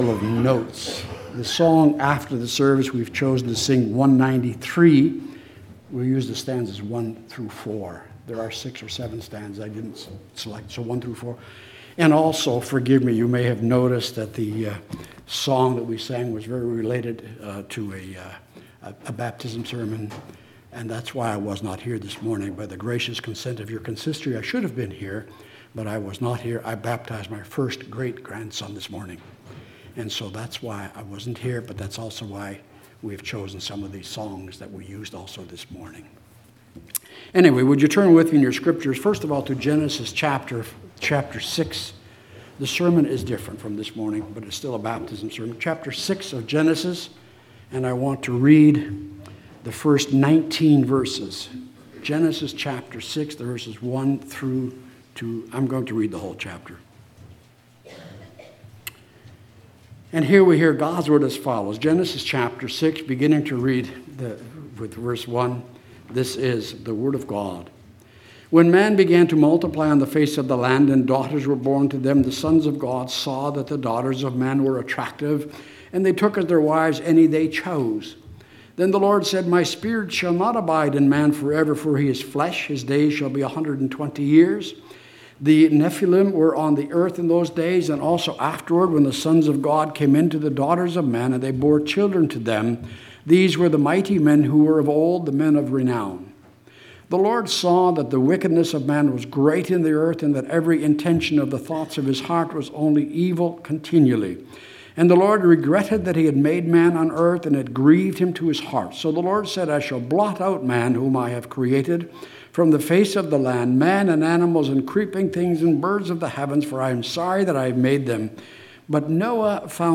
Passage: Genesis 6 Service Type: Sunday Afternoon